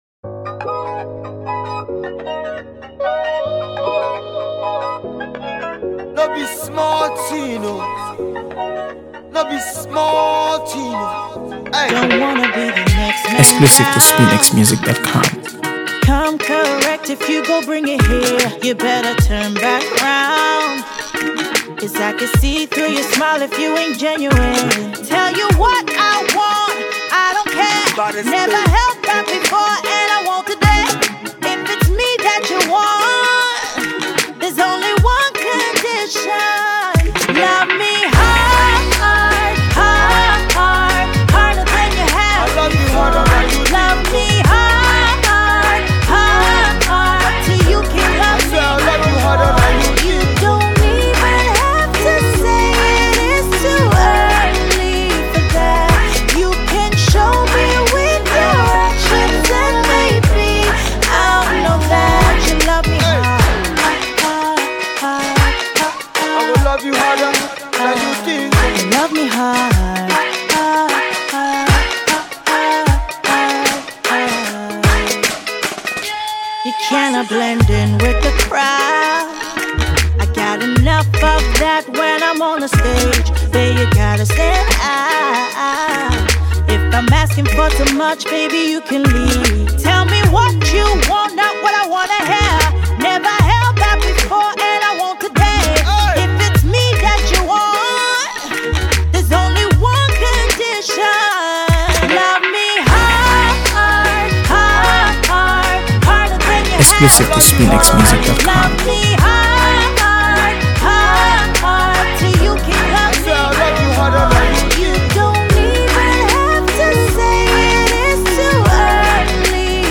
AfroBeats | AfroBeats songs
well-crafted ballads